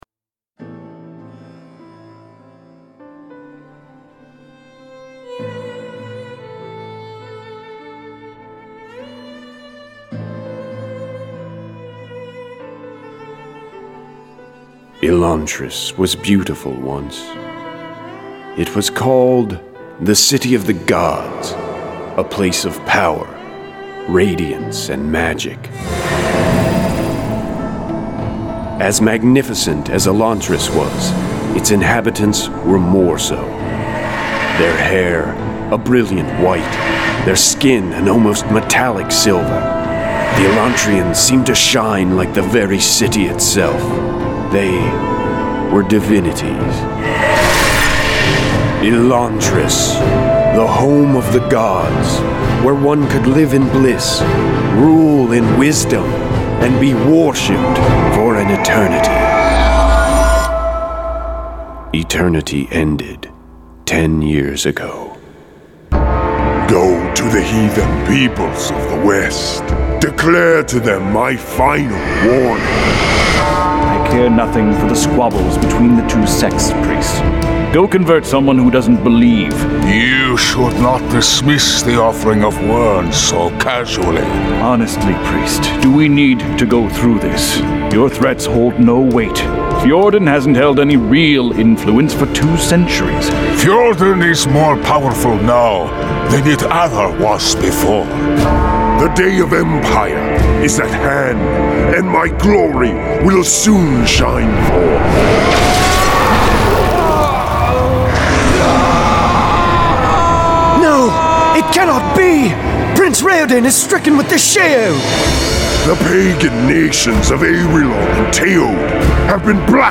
Full Cast. Cinematic Music. Sound Effects.
Genre: Fantasy